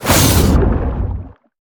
Sfx_creature_shadowleviathan_exoattack_loop_joystick_and_water_os_02.ogg